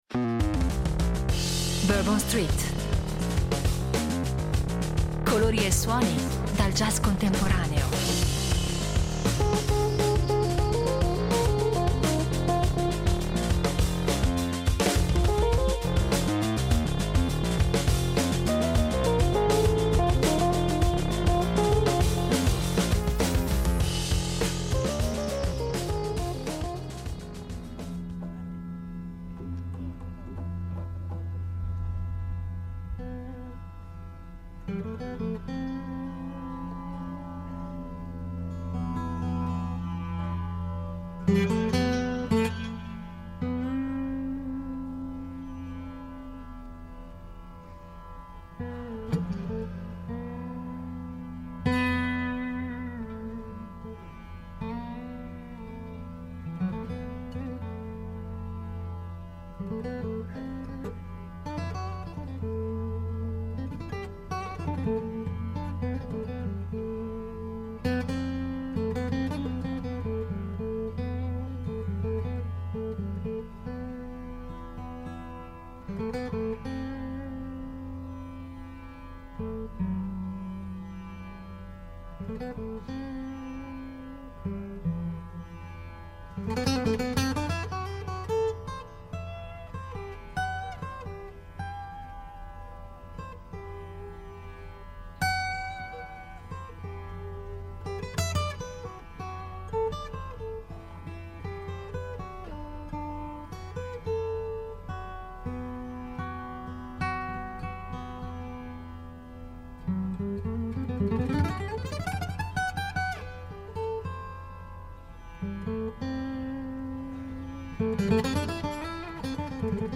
jazz tradizionale, carico di dramma e di vita
chitarrista jazz britannico
tablista indiano